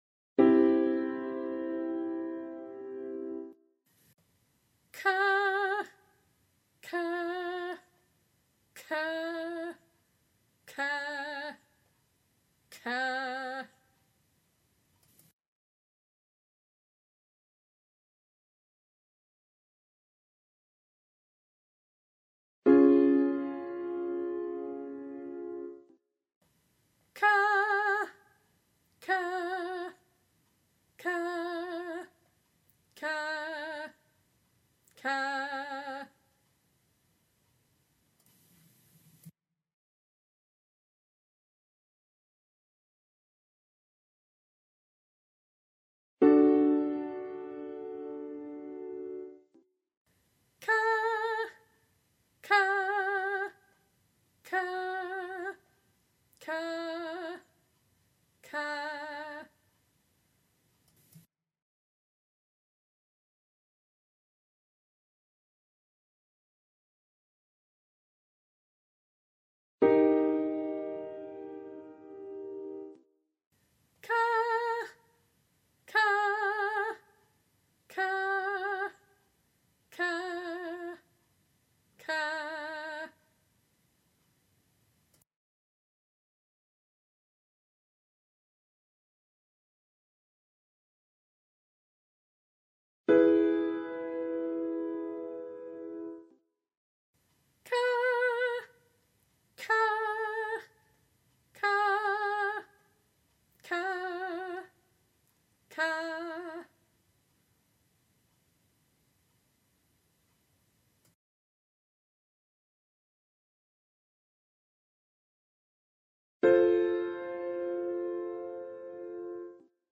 Singing Lessons and Vocal Coaching
76A Separated /kă/ as in “trap”